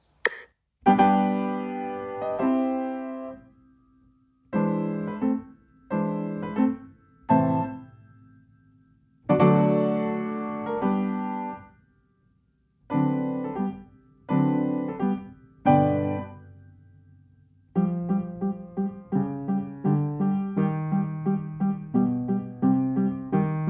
akompaniamentu pianina
Nagrania dokonane na pianinie Yamaha P2, strój 440Hz
piano